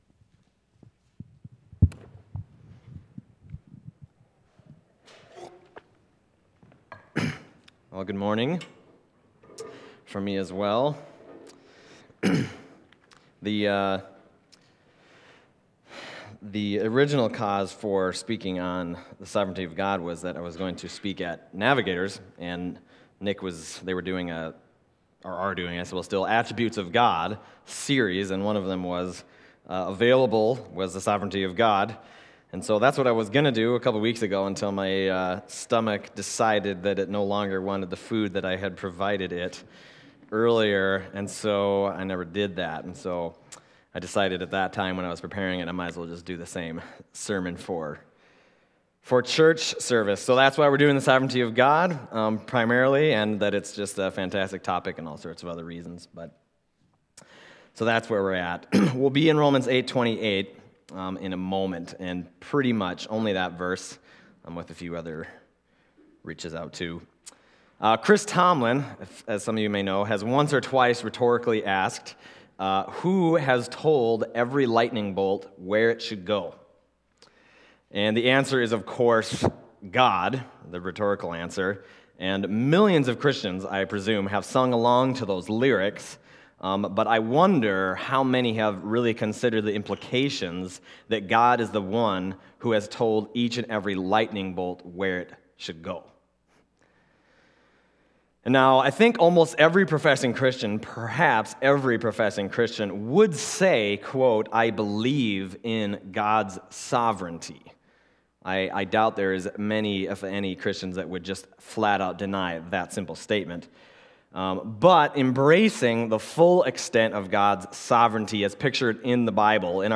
Passage: Romans 8:28 Service Type: Sunday Morning